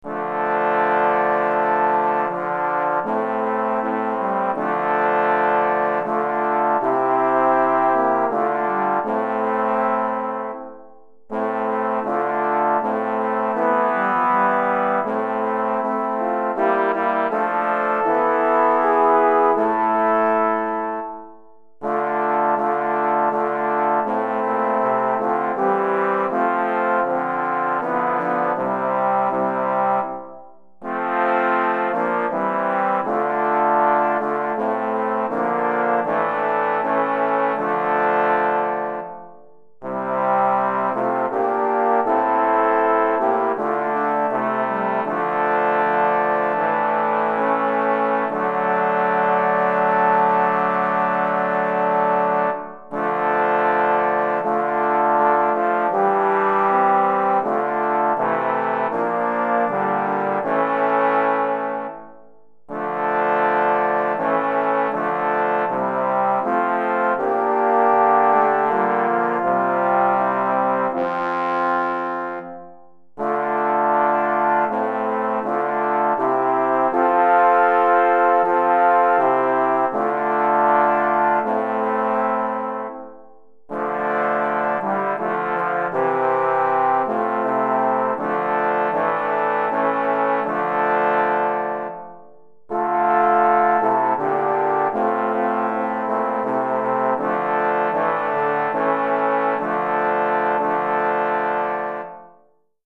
3 Trombones et Trombone Basse